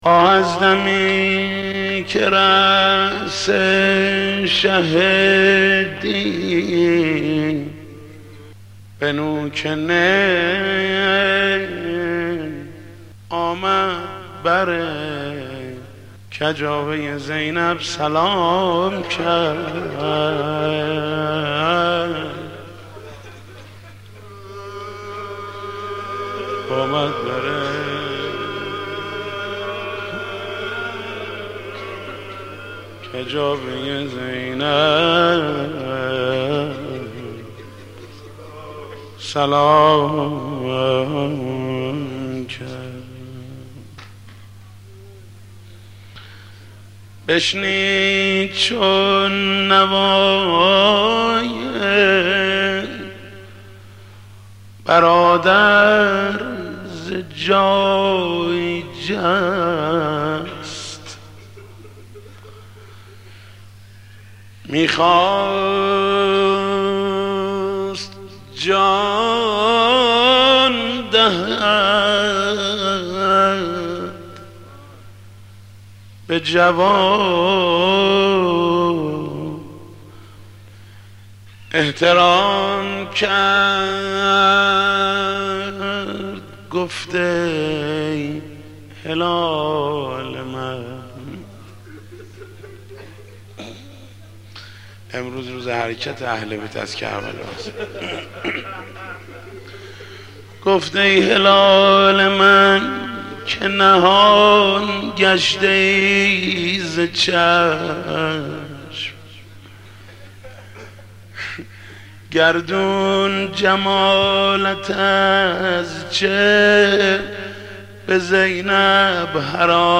مناسبت : دهه دوم محرم